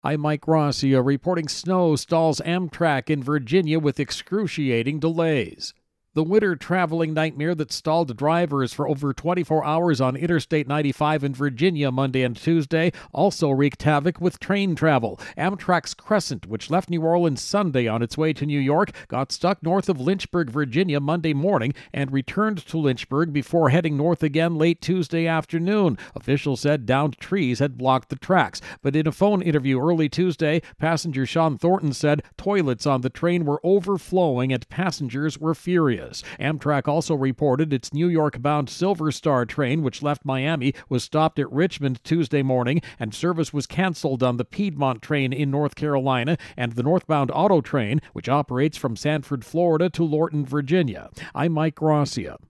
Winter Weather-Amtrak intro and voicer.